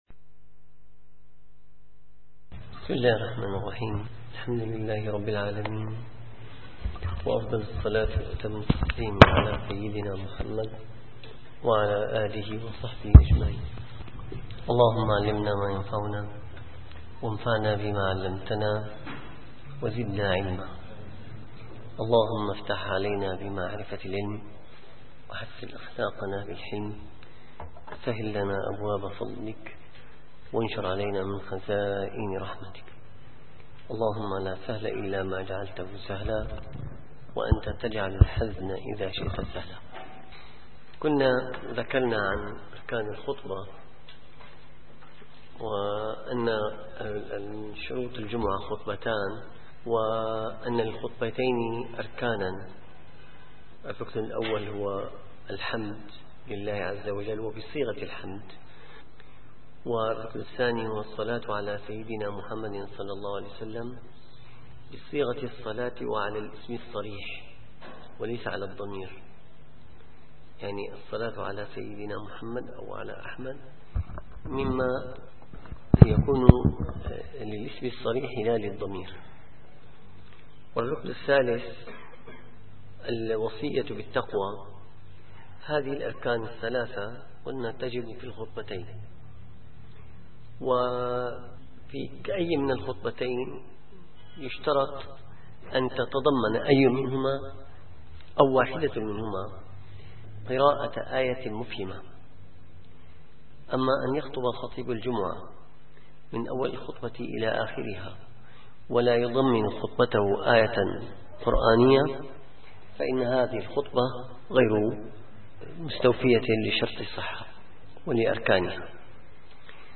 - الدروس العلمية - الفقه الشافعي - المنهاج القويم شرح المقدمة الحضرمية - في بعض سنن الخطبة وصلاة الجمعة (306)